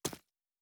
奔跑-右.wav